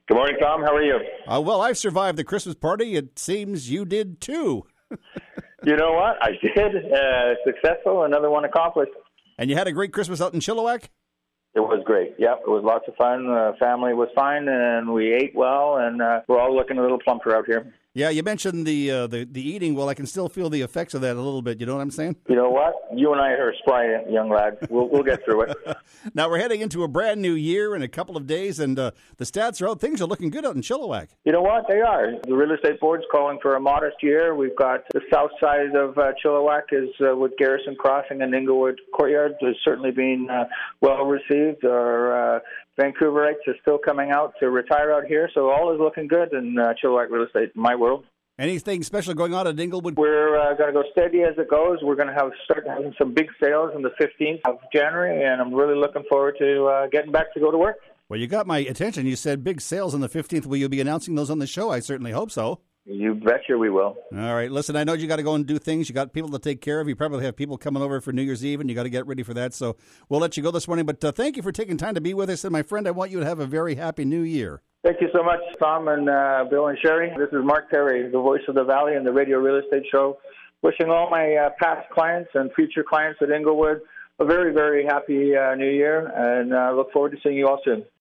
As 2014 draws to a close, we have one last Voice of the Valley segment on the Radio Real Estate Show (CISL 650) before the new year.